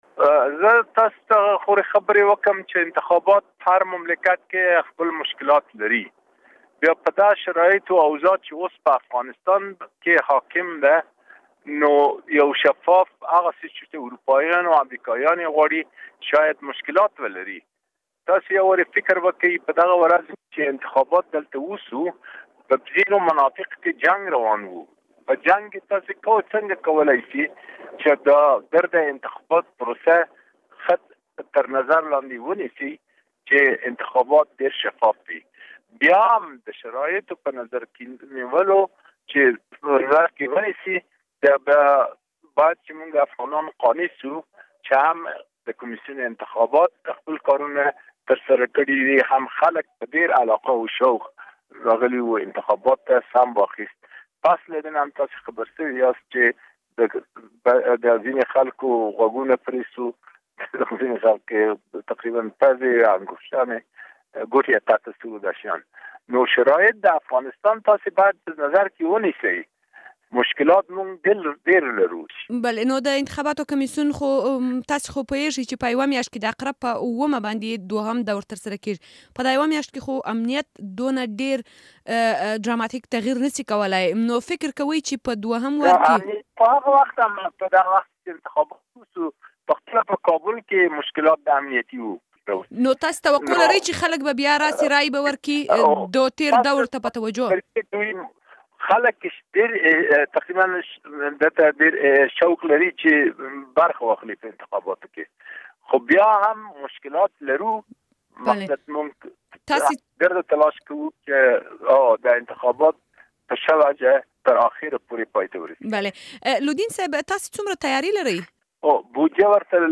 له عزیزالله لودین سره مرکه واورﺉ